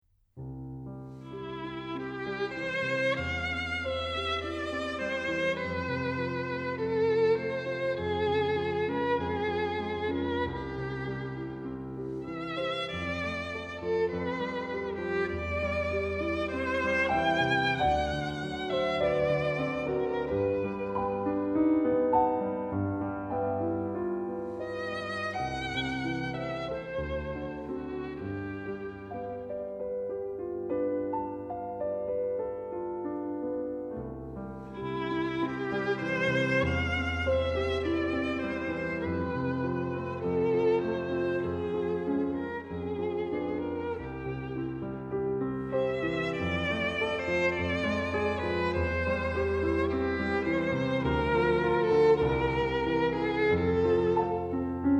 Violin
Piano)audio_joy.JPG